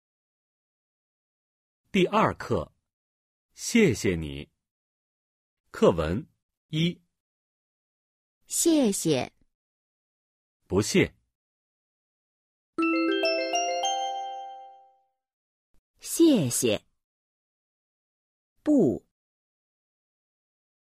(1) Hội thoại 1